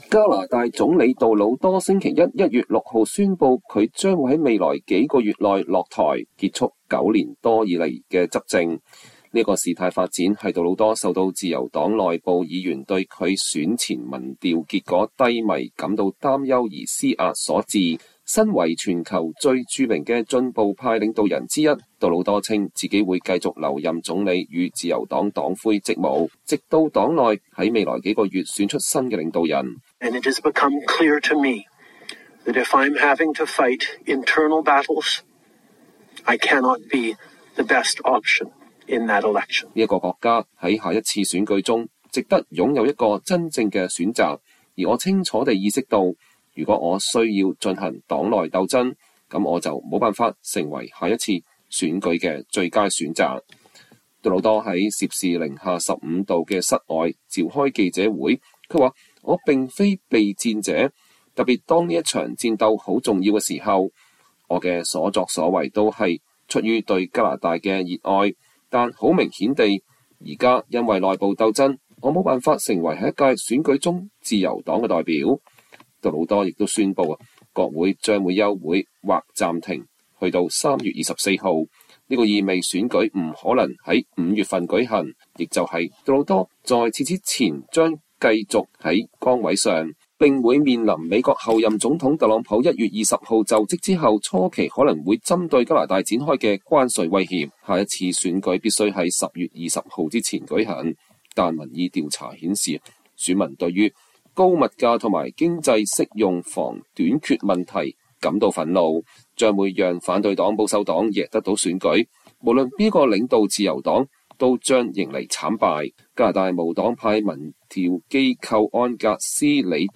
杜魯多在攝氏零下15度的室外開記者會，他說，“我並非避戰者，特別當這場戰鬥很重要的時候……我的所作所為都是出於對加拿大的愛，但很明顯地，現在因為內部鬥爭我無法成為下一屆選舉中自由黨的代表。”